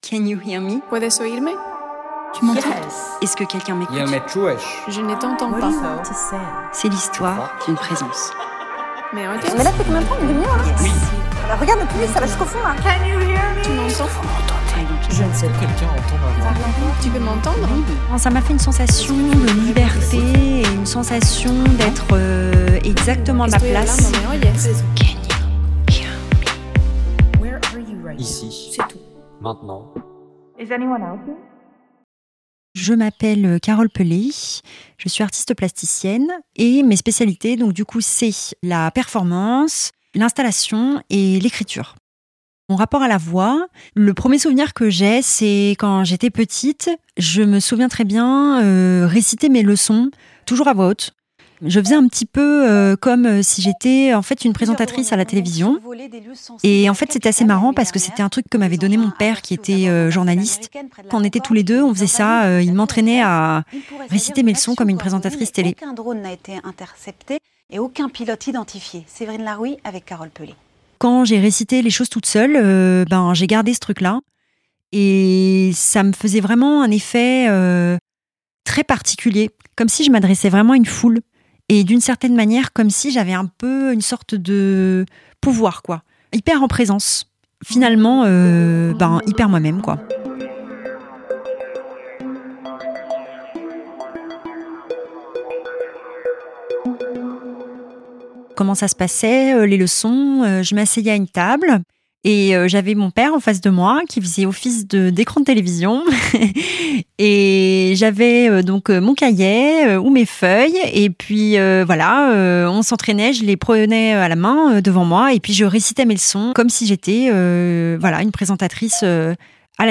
In this first episode, I trace the thread of my voice, where it comes from, how it imposed itself, and why it led me to this podcast. Between journalism, art school and the calling of becoming an artist, I tell my story as an intimate and essential introduction.
Création sonore